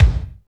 Index of /90_sSampleCDs/Northstar - Drumscapes Roland/DRM_Techno Rock/KIK_F_T Kicks x